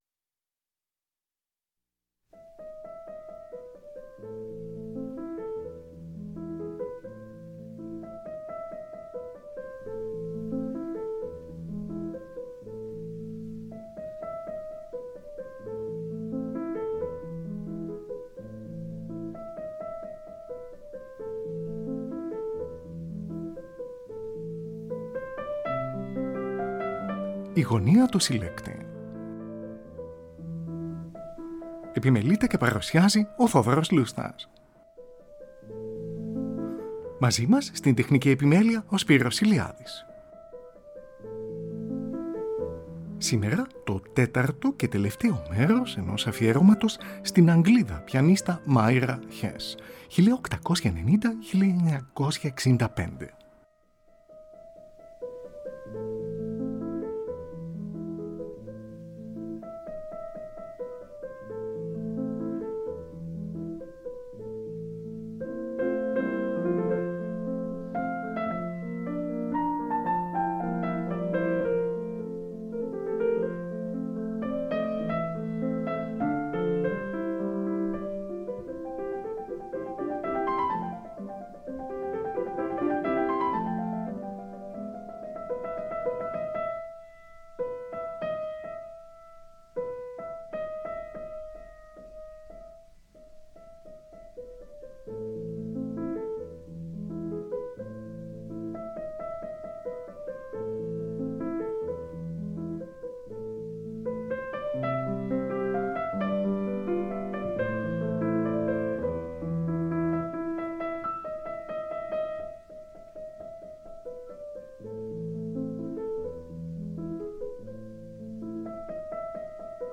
Ακούγονται συνθέσεις των Johann Sebastian Bach, Wolfgang Amadeus Mozart, Franz Schubert, Johannes Brahms, Tobias Matthay, Howard Ferguson κ.ά.